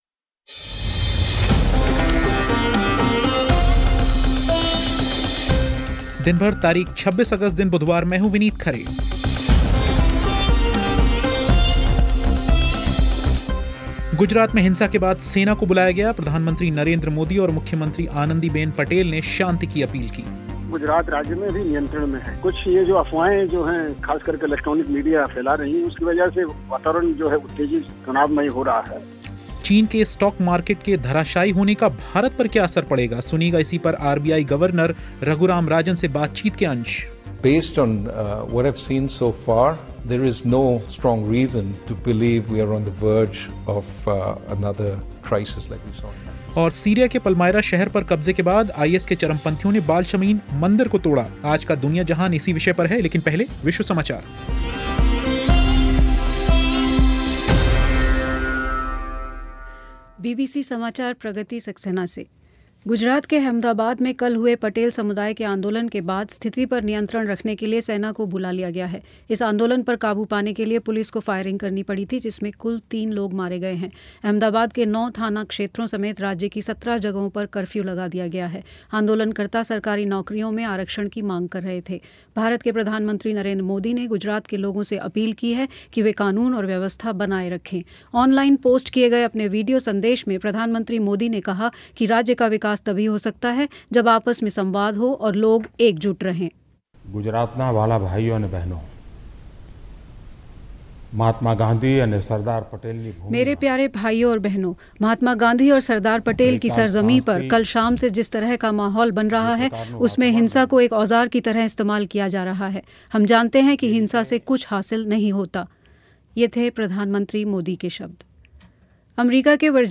चीन के स्टॉक मार्केट के धराशाई होने का भारत पर क्या असर पड़ेगा, सुनिएगा इसी पर आरबीआई गवर्नर से बातचीत के अंश